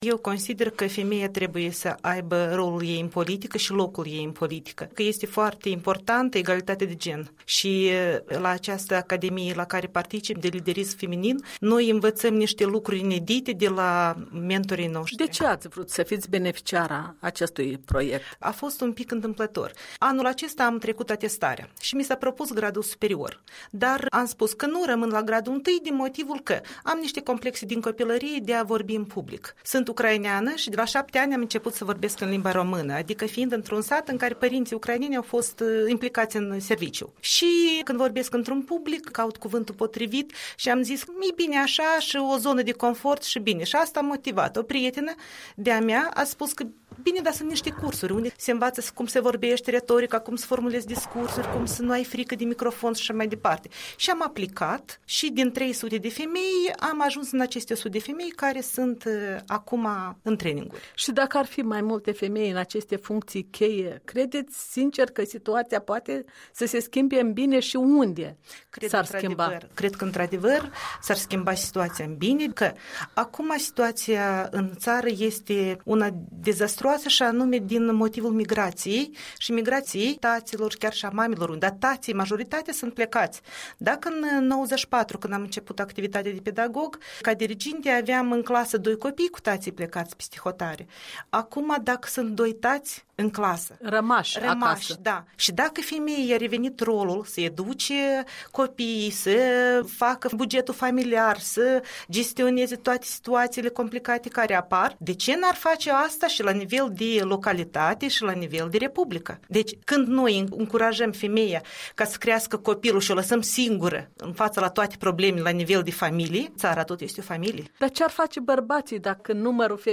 Interviu cu o profesoară de matematică din Pepeni care vrea să se implice în procesul decizional.